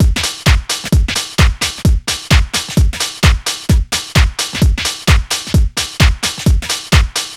Downtown House
Drum Loops 130bpm